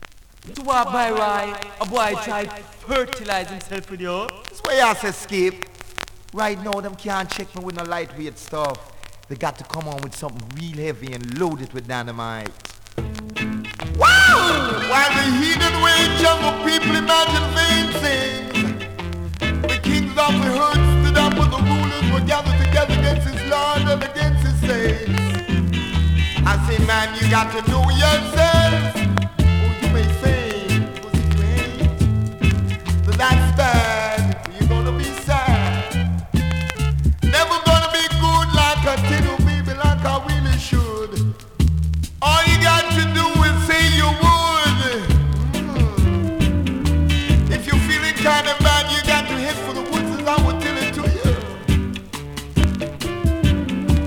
スリキズ、ノイズそこそこありますが